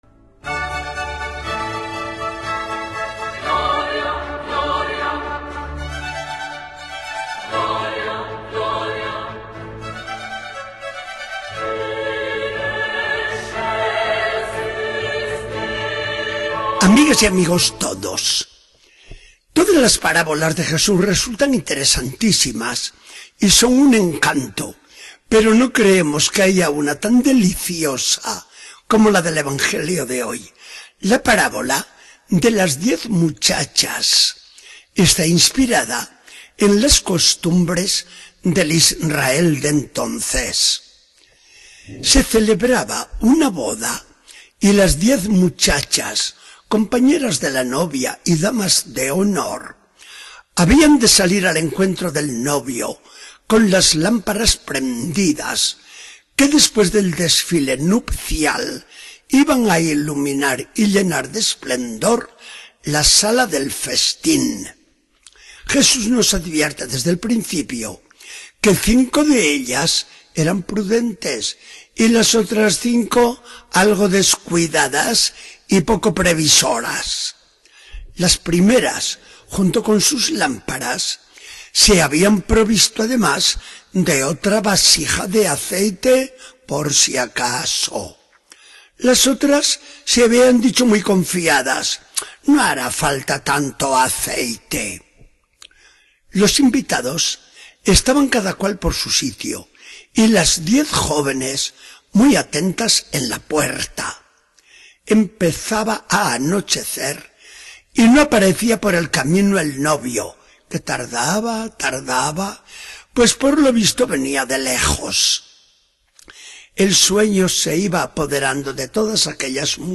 Charla del día 9 de noviembre de 2014. Del Evangelio según San Mateo 25, 1-13.